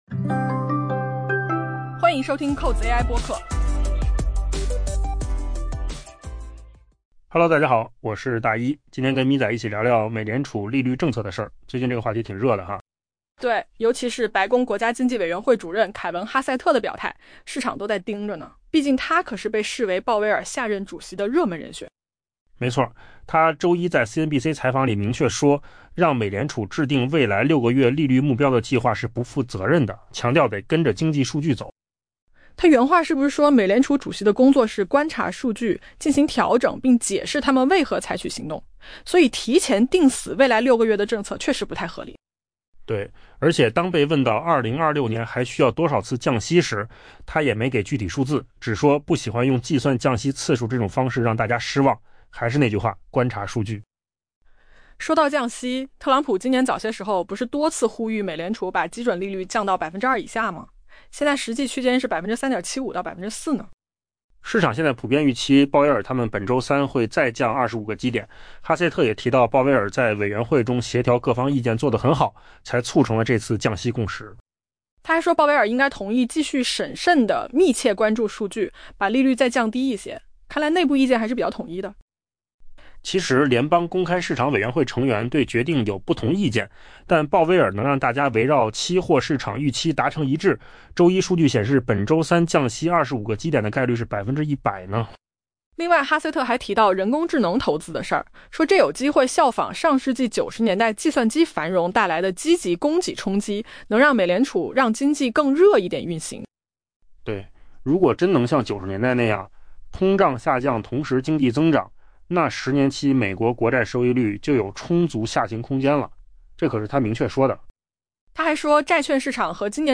AI 播客：换个方式听新闻 下载 mp3 音频由扣子空间生成 白宫国家经济委员会主任、目前被市场视为最有可能接替鲍威尔的美联储下任主席人选凯文·哈塞特 （Kevin Hassett） 表示， 让美联储制定未来六个月利率目标的计划是不负责任的，他强调遵循经济数据的重要性。